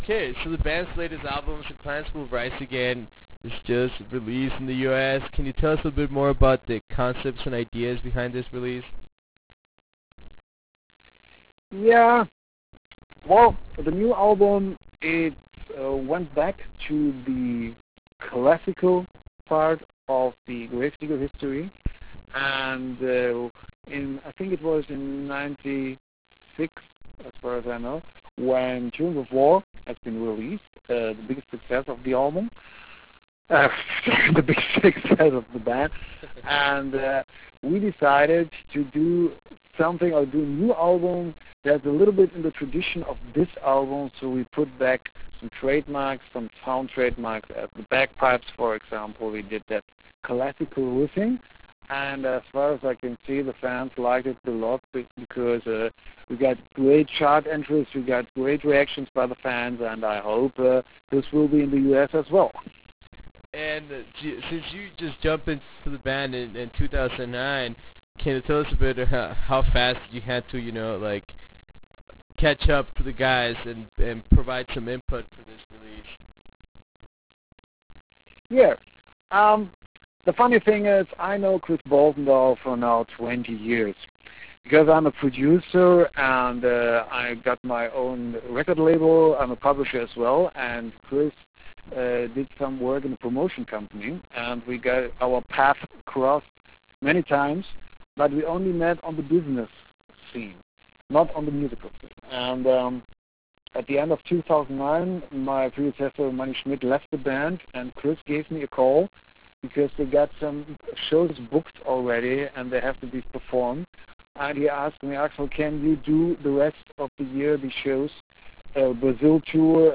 Interview with Grave Digger